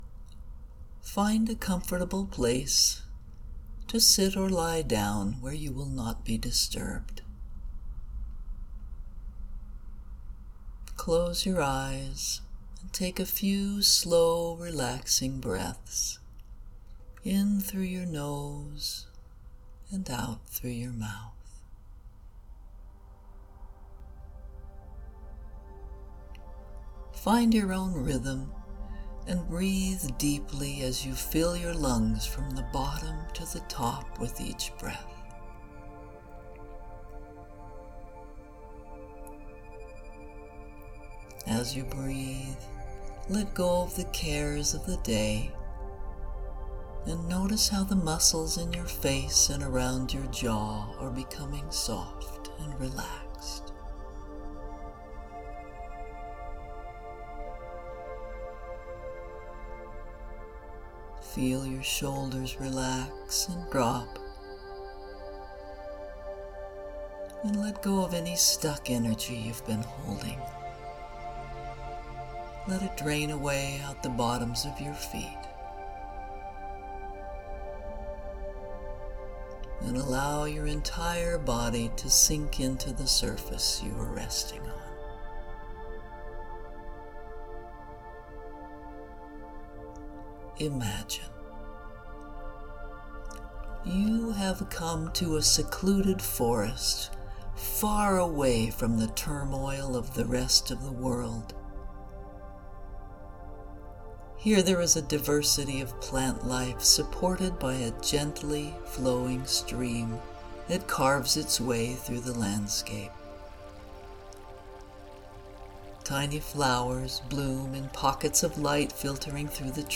September 2025 Meditation:   Visit a secluded forest and soak up the frequencies of the natural world while sitting in a magical pool of water. Feel your connection with the water and with Mother Earth and send love and gratitude to all life everywhere. Music in this Meditation by Relaxing Time at Pixabay; Sound effects by Acerting Art.